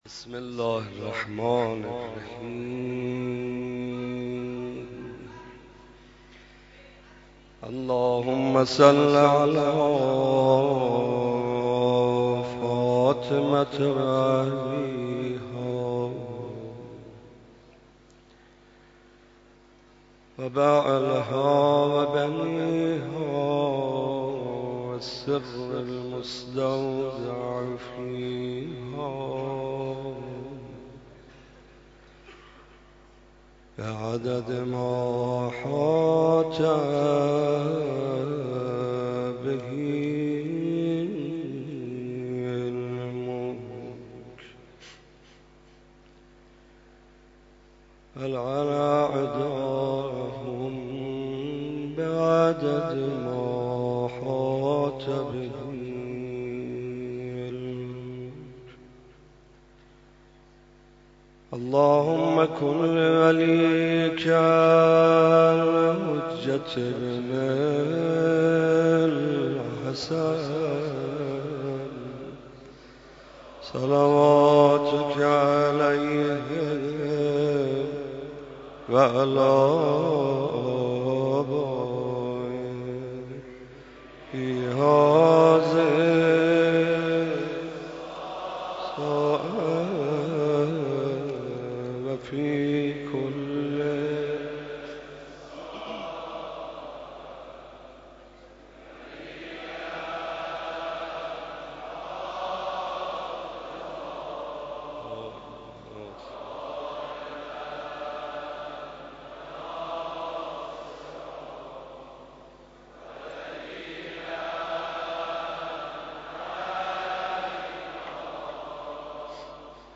حاج محمدرضا طاهری/دومین شب عزاداری فاطمیه در حضور رهبر انقلاب